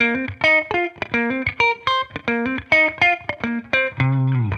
Index of /musicradar/sampled-funk-soul-samples/105bpm/Guitar
SSF_TeleGuitarProc2_105C.wav